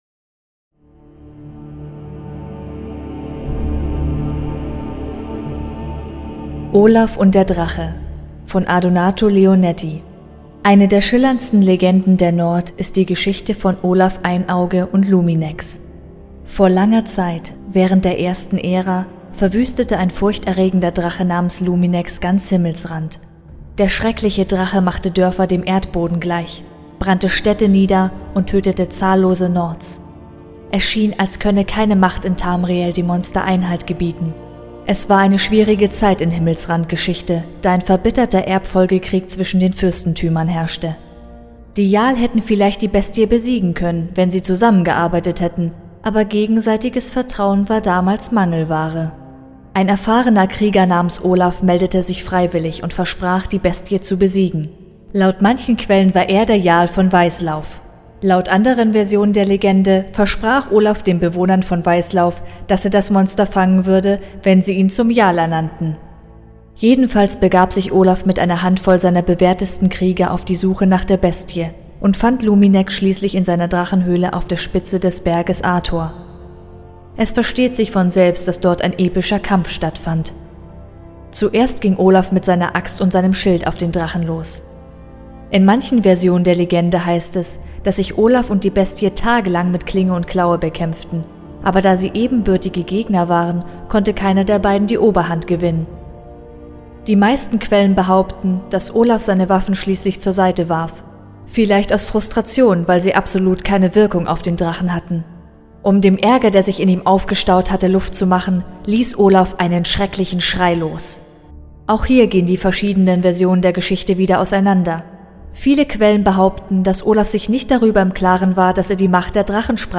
Ein Hörbuch zum Buch Olaf und der Drache.